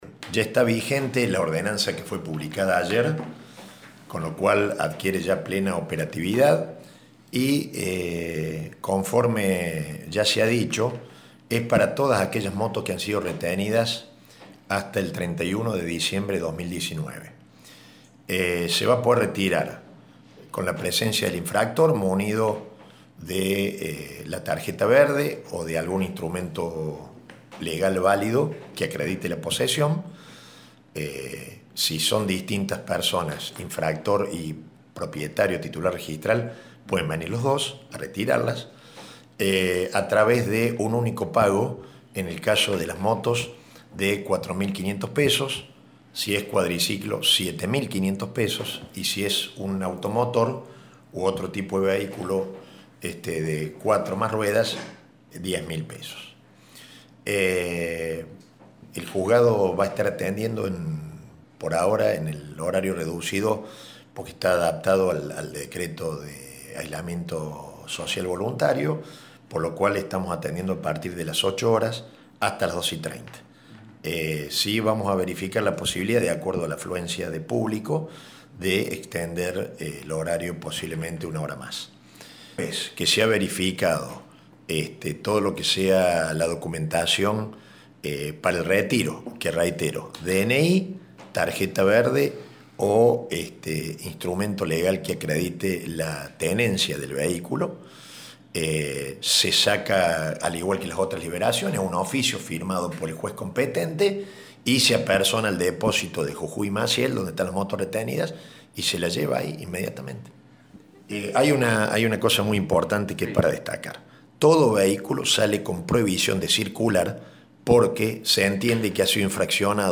El juez de Faltas municipales Julio Aliciardi habló con Radio Show.